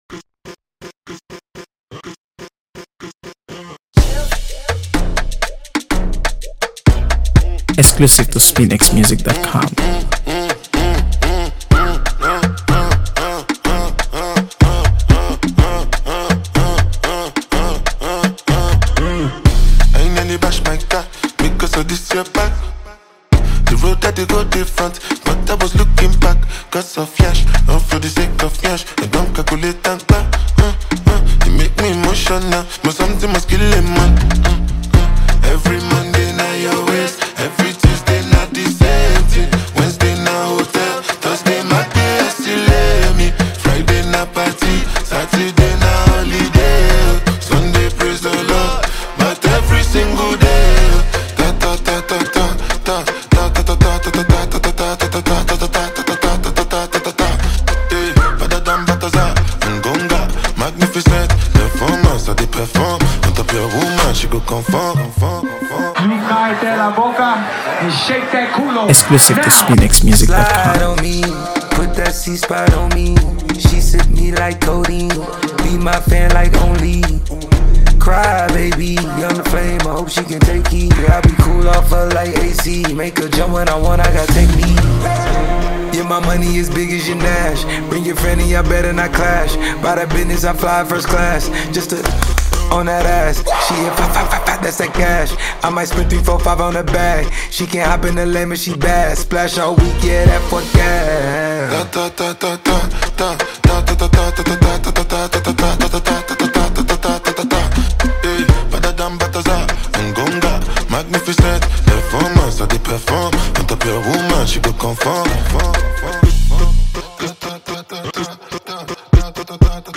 AfroBeats | AfroBeats songs
With its infectious beat and confident delivery